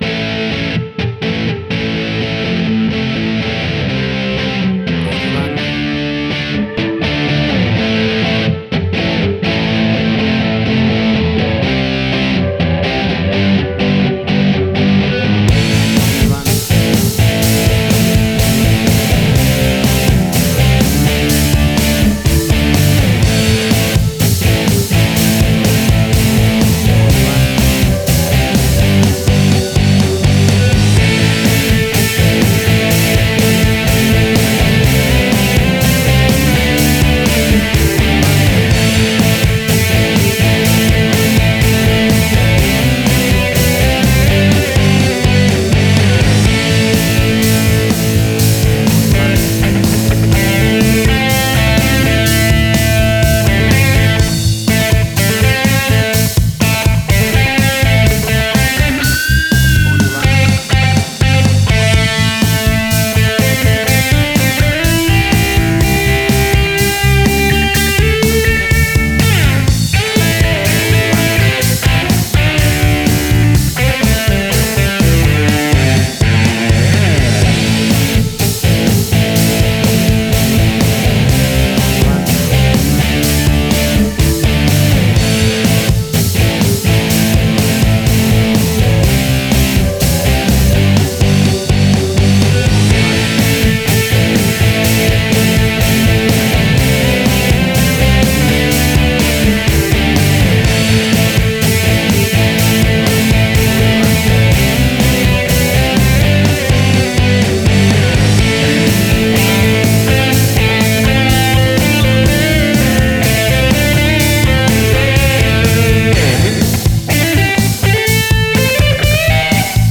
A classic big rock riff guitar led track!
Tempo (BPM): 124